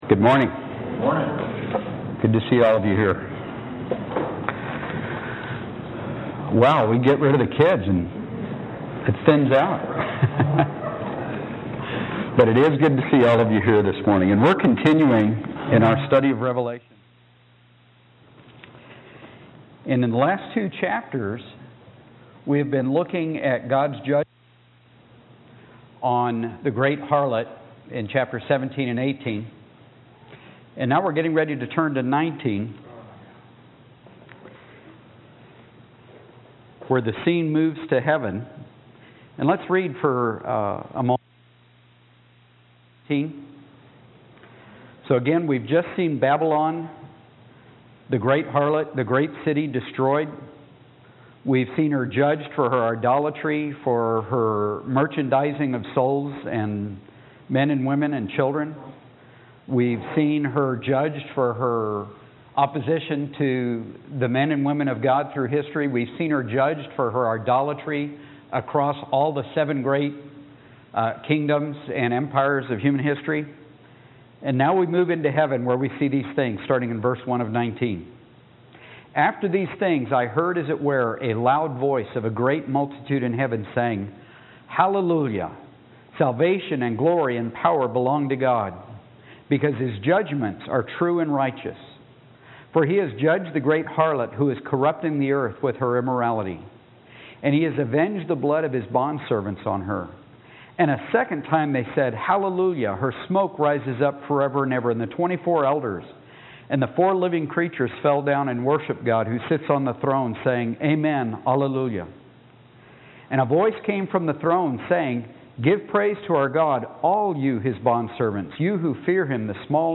Note:There are some audio problems at the beginning, but a few seconds into the recording we have it working right, and we are finally getting really clean recordings here. I hope you enjoy the improvement in audio quality.